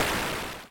Play Weapon Hit 1 Among Us - SoundBoardGuy
Play, download and share Weapon Hit 1 Among Us original sound button!!!!
panel_weaponhit1-online-audio-converter.mp3